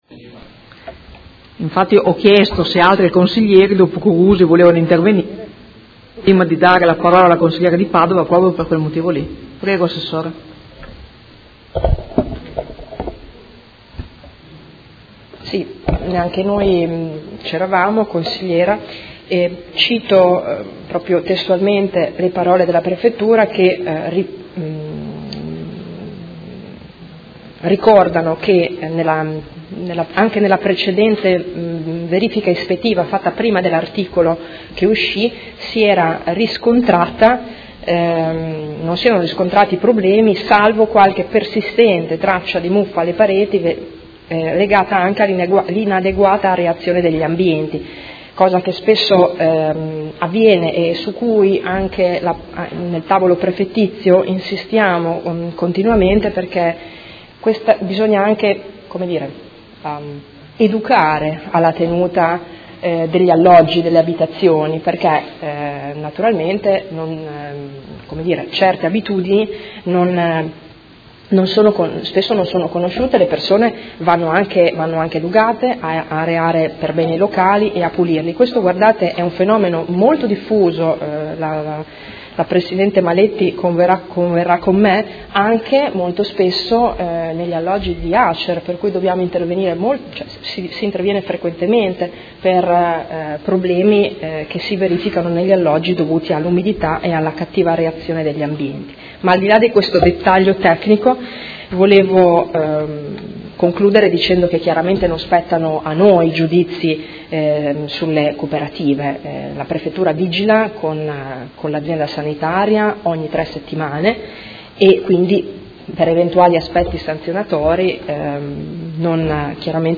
Seduta del 3/05/2018. Conclude dibattito su interrogazione dei Consiglieri Di Padova, Poggi, Bortolamasi e Fasano (PD) avente per oggetto: Condizioni in cui i profughi trovano ospitalità presso le cooperative che operano sul territorio di Modena